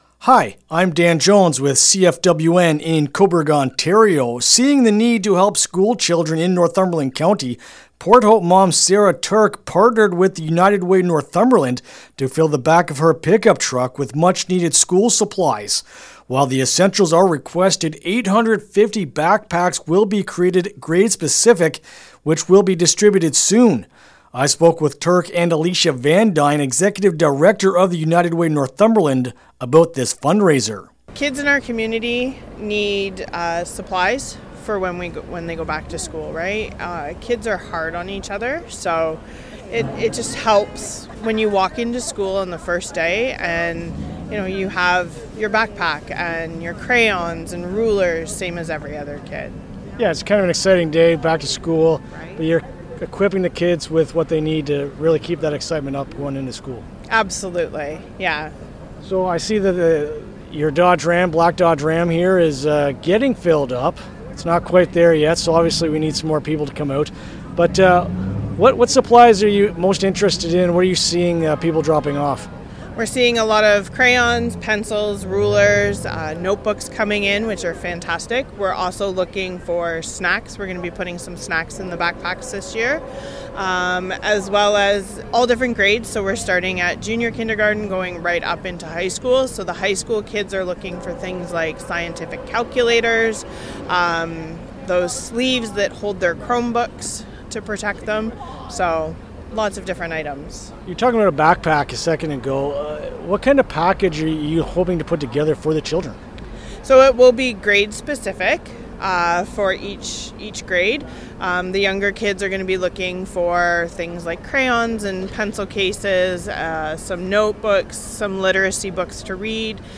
Fill-the-Ram-Interview-LJI.mp3